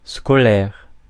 Ääntäminen
Ääntäminen France (Paris): IPA: /skɔ.lɛʁ/ Haettu sana löytyi näillä lähdekielillä: ranska Käännös Ääninäyte Adjektiivit 1. school UK 2. scholastic 3. academic US 4. bookish Suku: f .